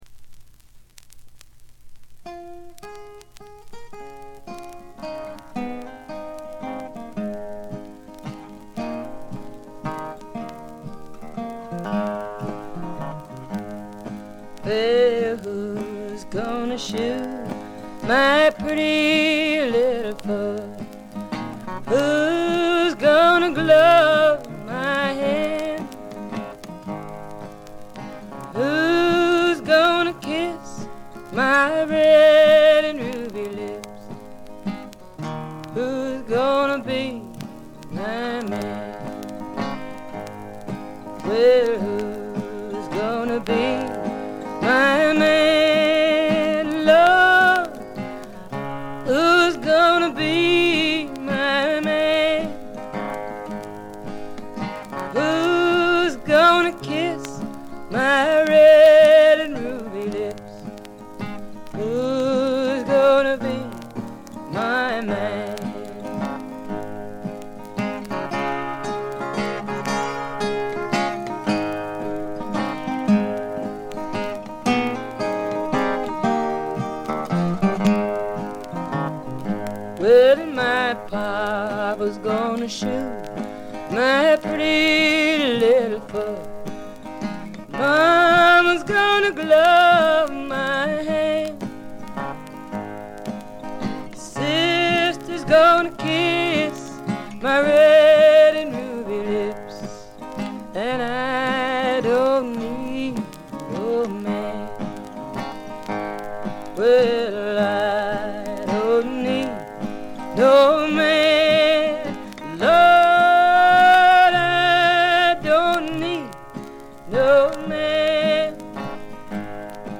バックグラウンドノイズ、チリプチは常時大きめに出ます。
存在感抜群のアルト・ヴォイスが彼女の最大の武器でしょう。
試聴曲は現品からの取り込み音源です。